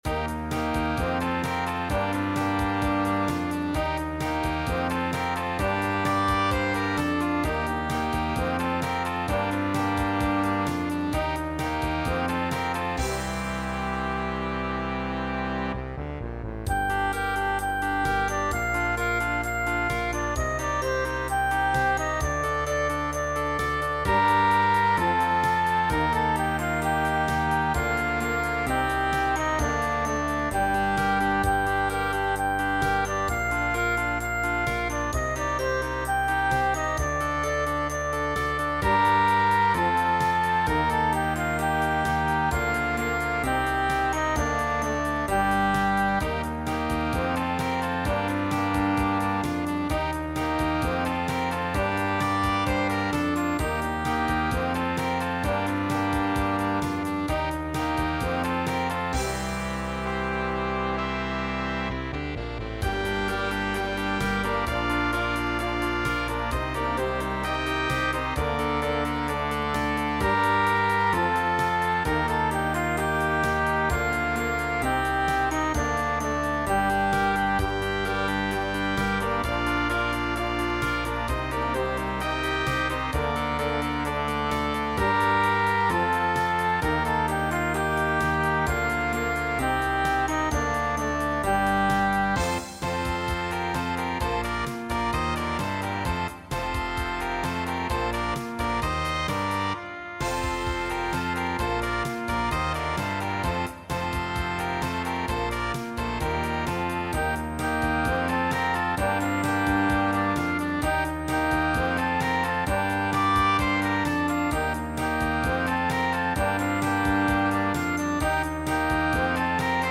Latviešu tautasdziesma "Div' dūjiņas".